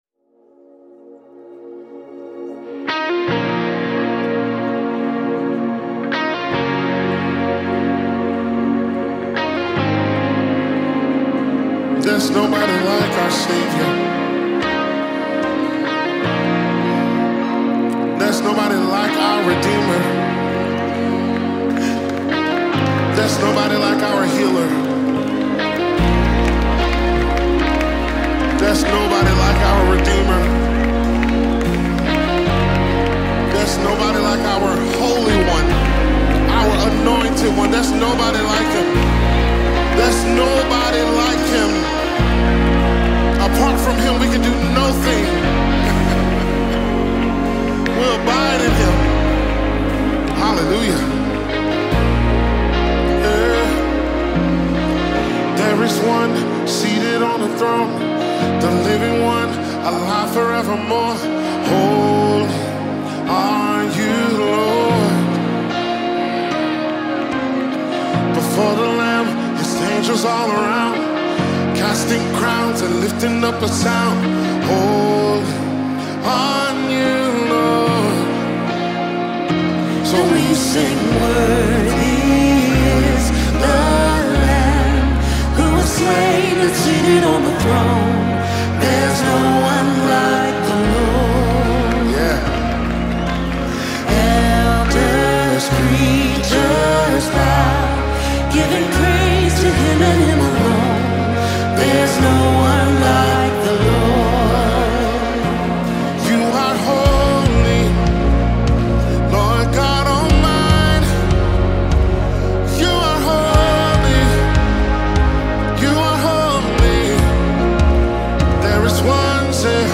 2024 single